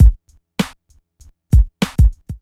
Beat 020-44S.wav